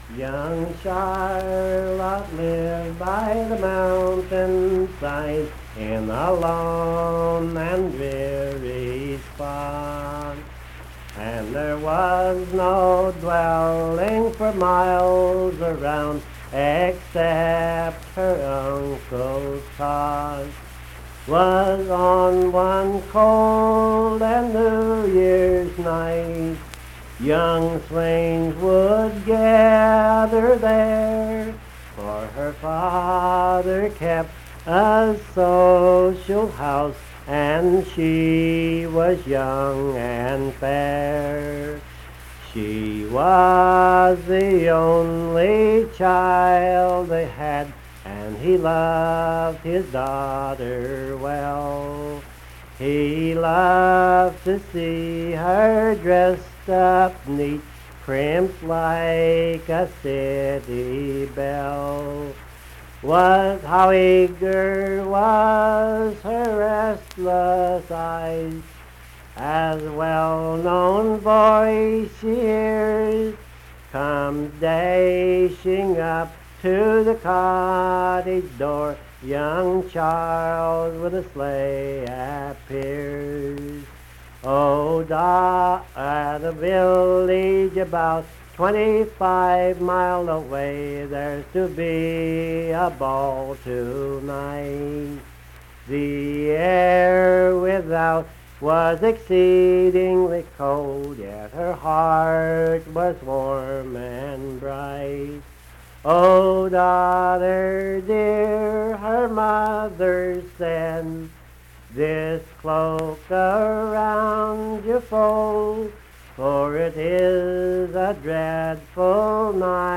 Unaccompanied vocal music
Performed in Hundred, Wetzel County, WV.
Voice (sung)